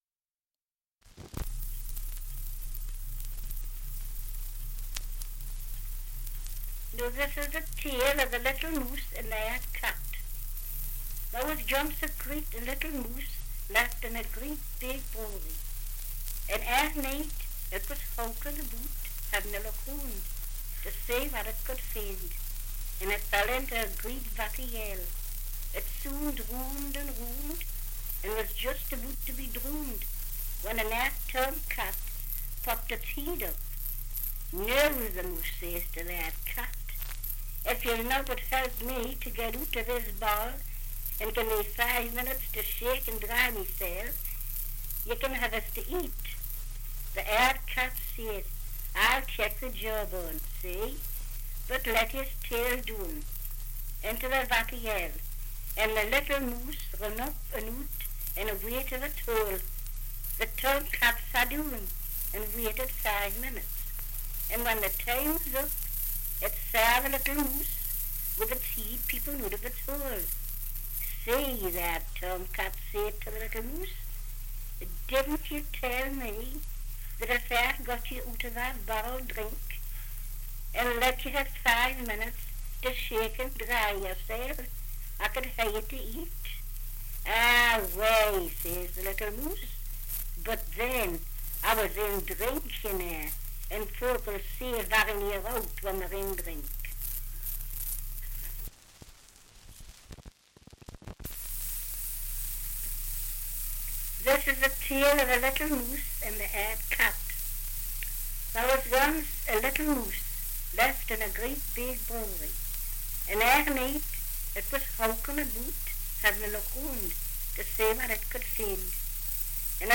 Dialect recording in Cullercoats, Northumberland
78 r.p.m., cellulose nitrate on aluminium